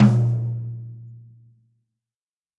Sonor Phonic9层山毛榉岩石套装汤姆中号13x9
描述：汤姆和踢腿的立体声录音，来自各种套件。
声道立体声